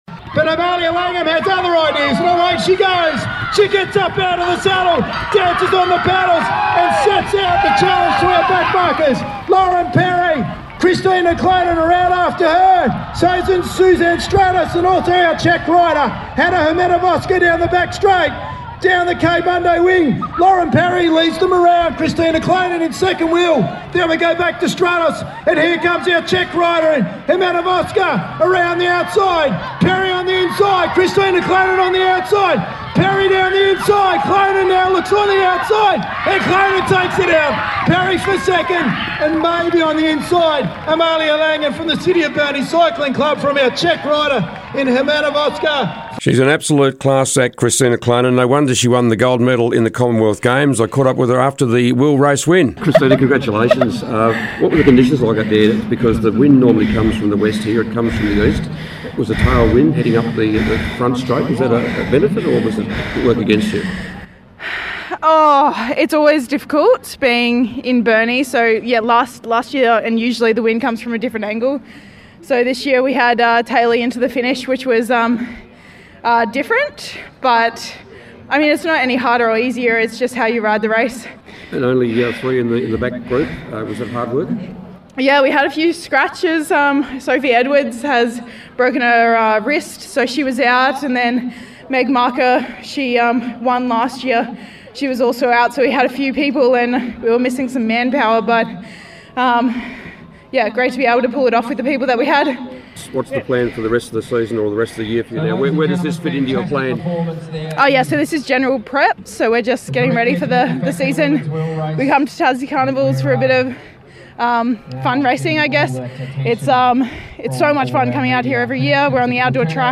Listen to the calls and interviews with winners from the Burnie New Year's Eve Carnival running and cycling programme